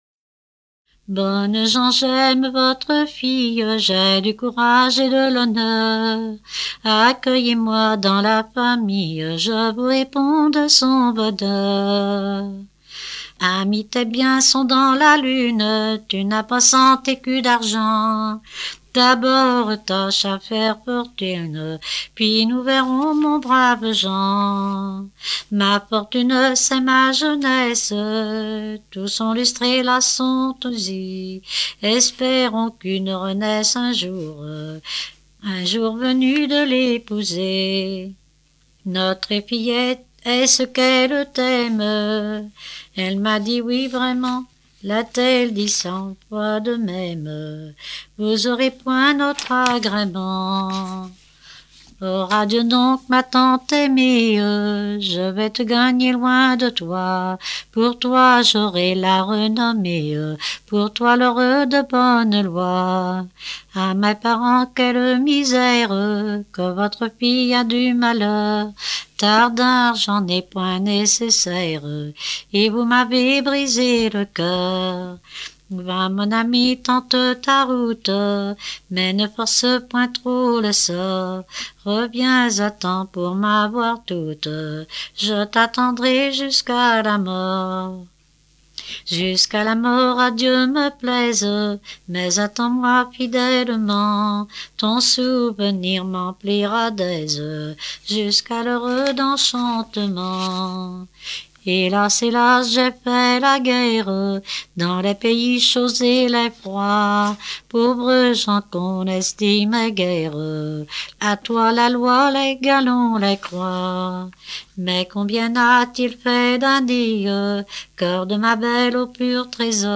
circonstance : fiançaille, noce
Genre strophique
Pièce musicale éditée